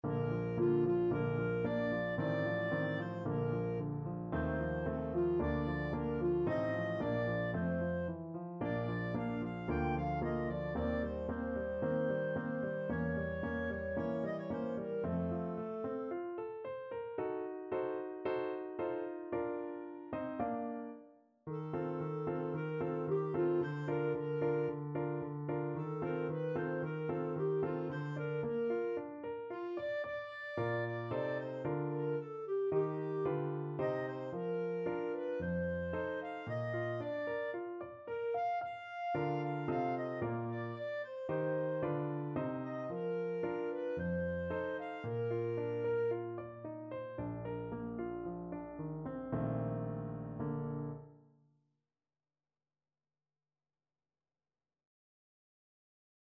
2/4 (View more 2/4 Music)
~ = 56 Affettuoso
Classical (View more Classical Clarinet Music)